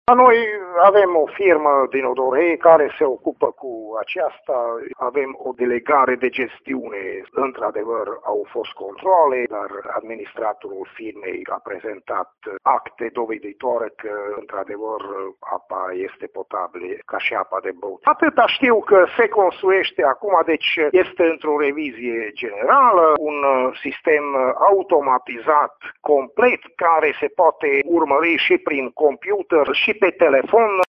Primarul Varga Jozsef spune că situația nu este atât de gravă și că stația este în curs de renovare iar în curând va fi una din cele mai moderne și performante din zonă: